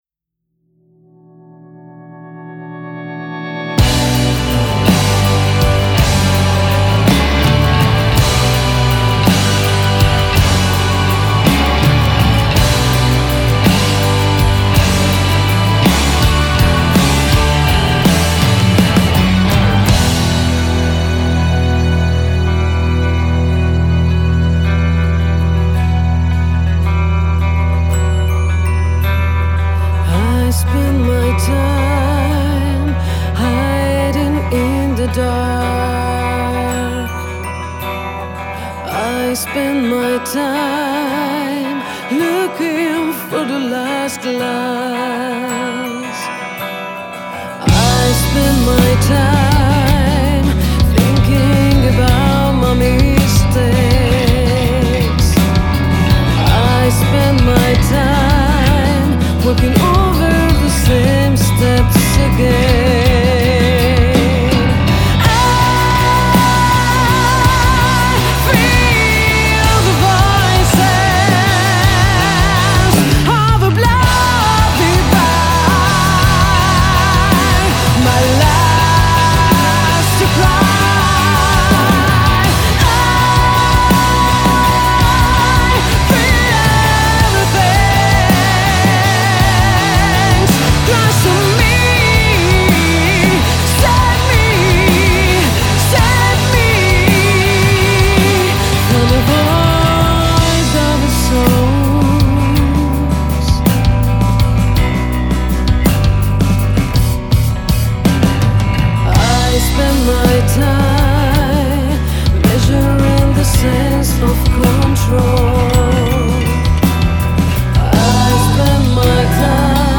Genere: Alt. Rock.